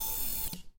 mechanism_down.ogg